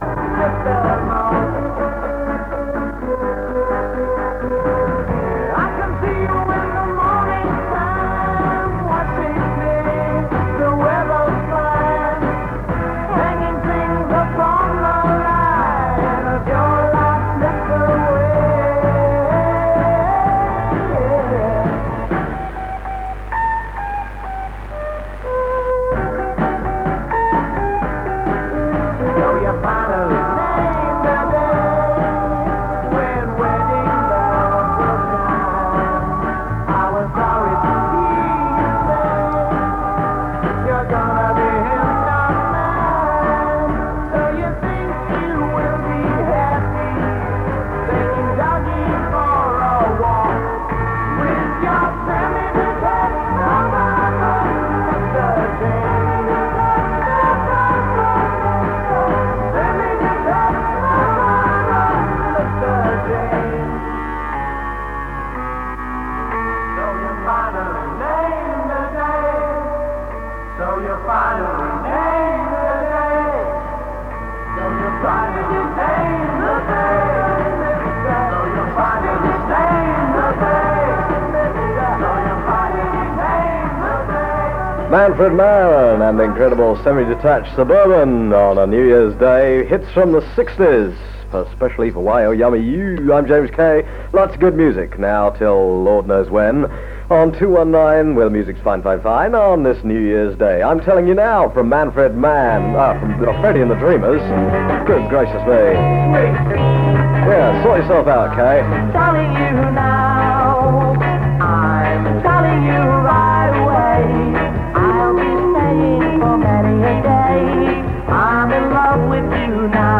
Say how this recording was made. Recorded in Chingford from 1368kHz. 43MB 47mins